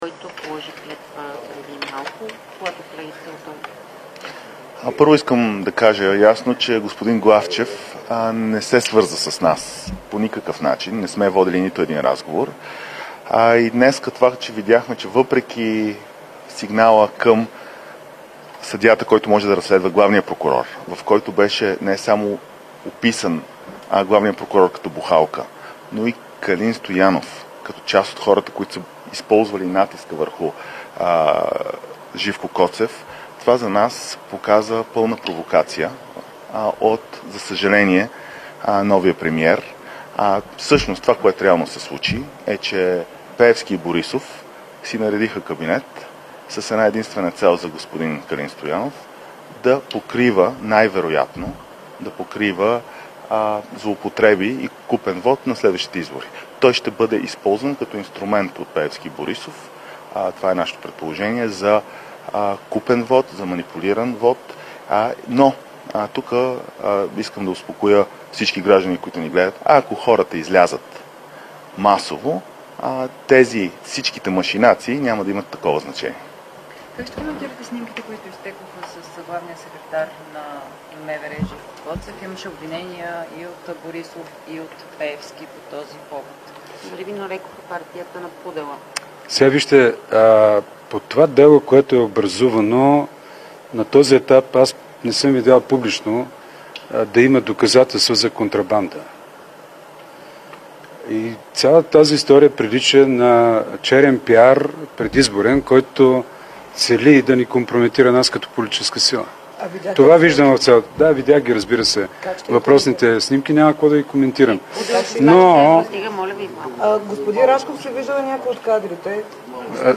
11.05 - „Индекс на болниците" заедно с Асоциацията на българските застрахователи (АБЗ) и Съвместна Онкологична Национална Мрежа (СОНМ) ще дадат пресконференция на тема „Достъп до онкологична грижа". Доплащат ли българските пациенти при лечението си на онкологични заболявания и колко?
Директно от мястото на събитието